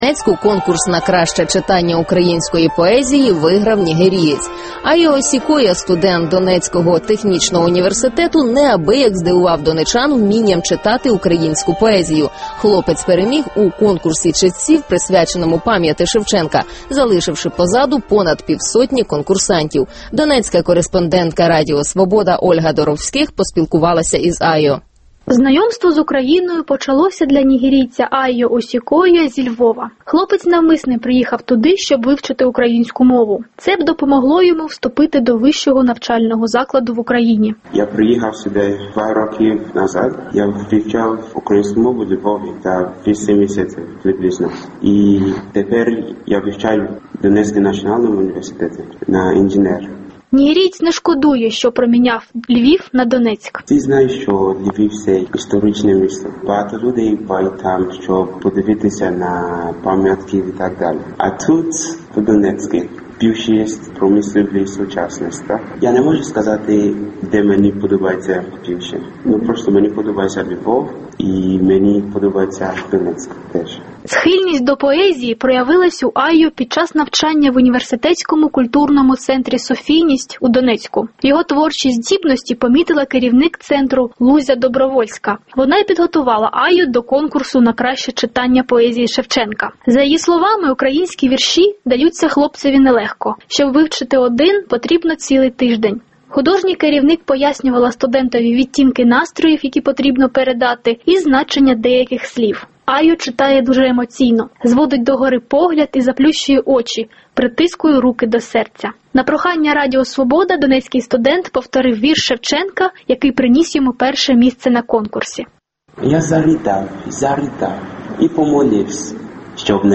У Донецьку блискуче читає українські вірші студент із Нігерії